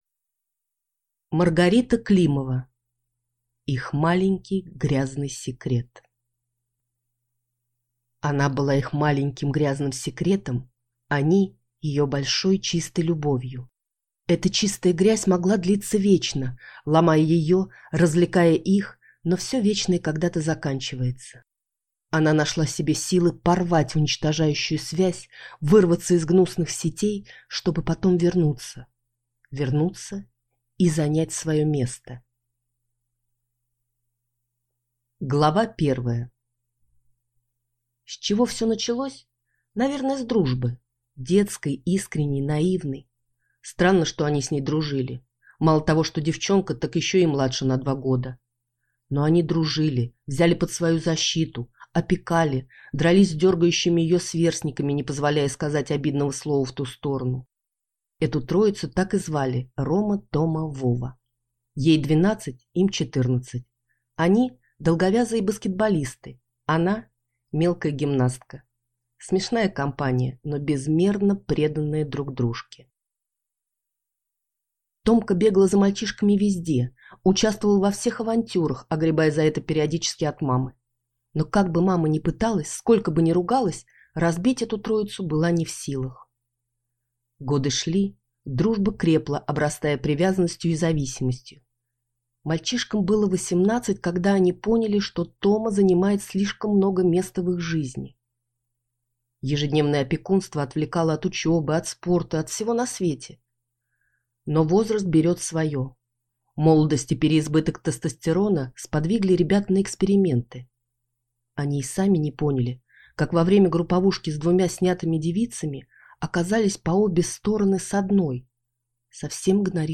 Аудиокнига Их маленький грязный секрет | Библиотека аудиокниг